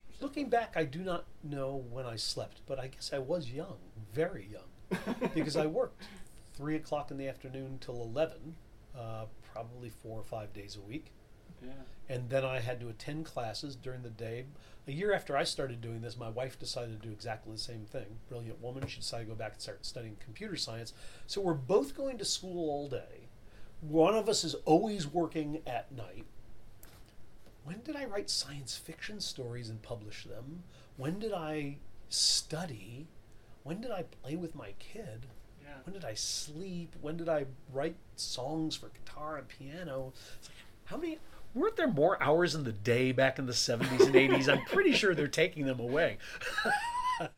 Dr. Gilbert candidly describes how he made it through his education while being a parent, his spouse going to school and working, and his hours working to support himself.
As I hope is clear,  Dr. Gilbert is full of laughter and humor in his descriptions, undoubtedly another helpful trait to make it through sleepless nights and long days.